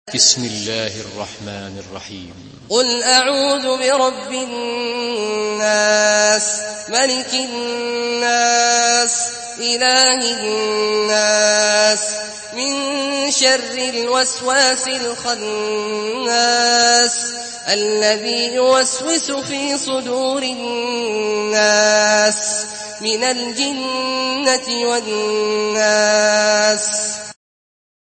سورة الناس MP3 بصوت عبد الله الجهني برواية حفص
مرتل حفص عن عاصم